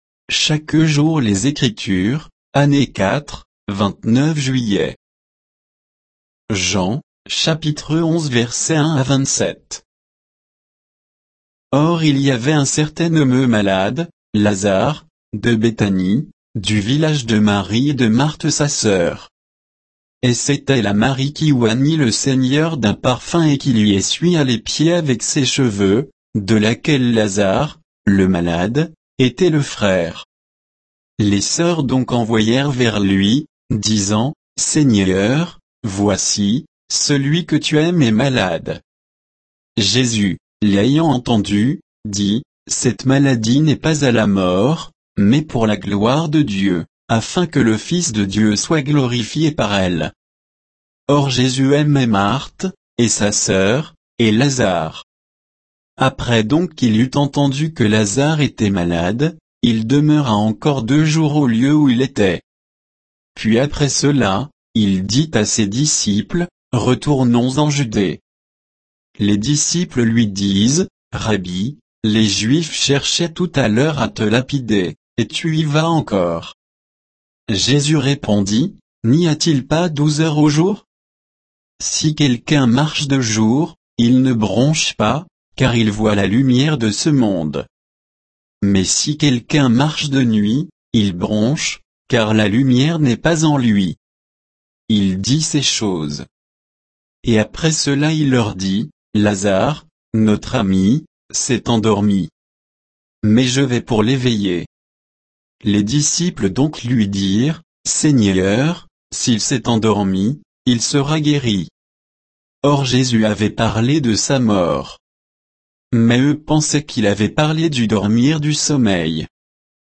Méditation quoditienne de Chaque jour les Écritures sur Jean 11